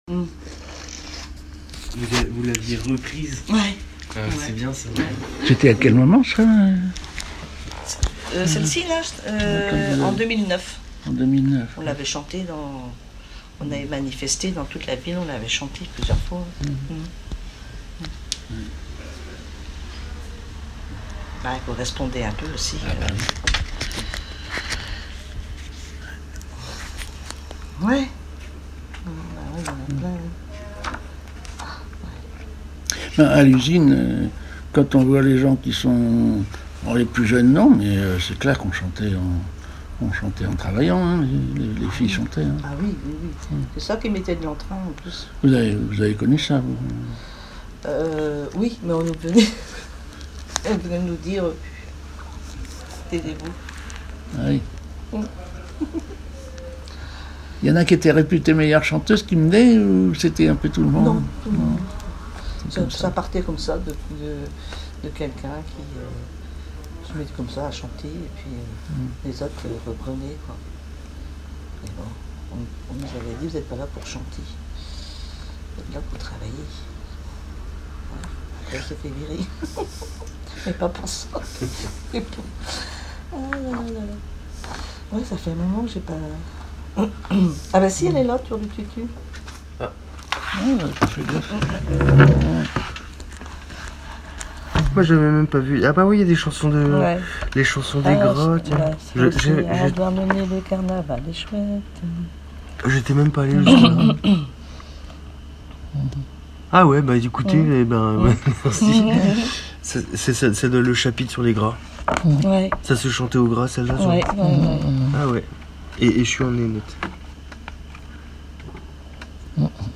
Enquête Douarnenez en chansons
Catégorie Témoignage